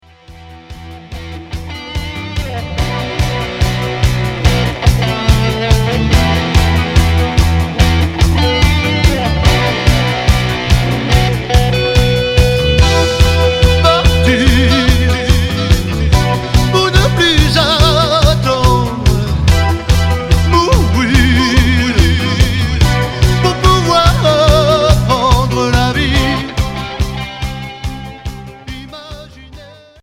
Hard FM